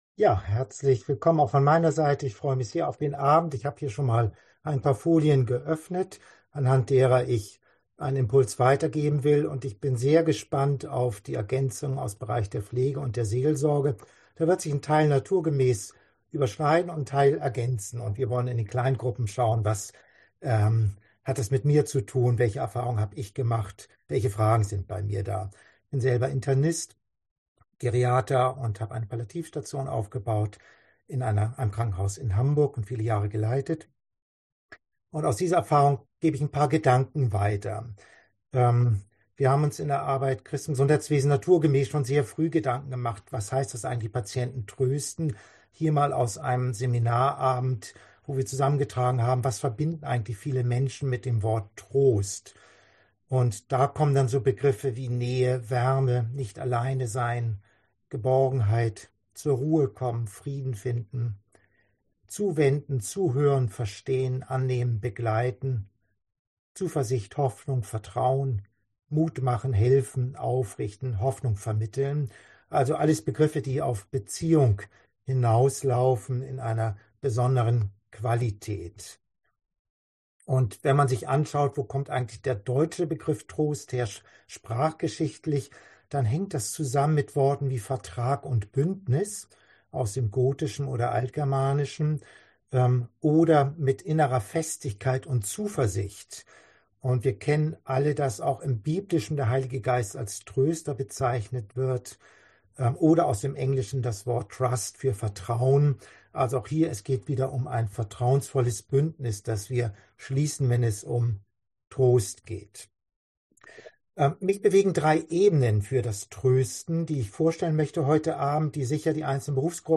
Digitales Kurzseminar